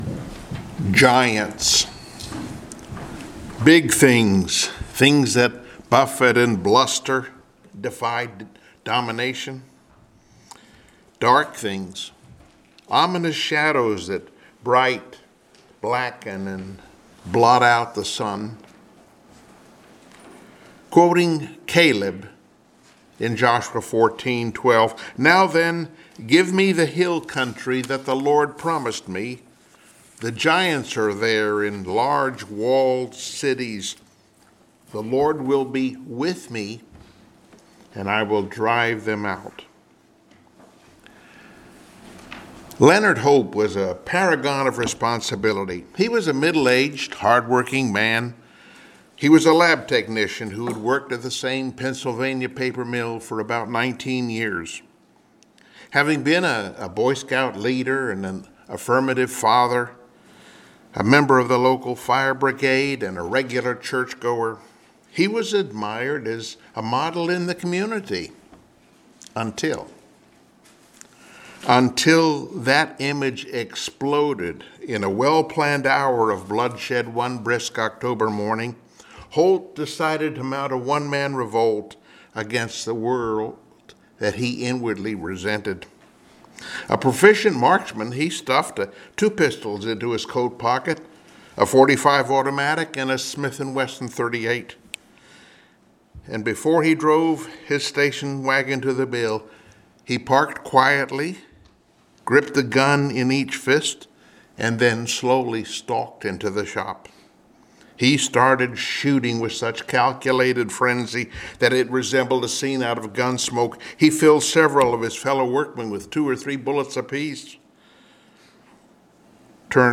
Passage: 1 Samuel 18 Service Type: Sunday Morning Worship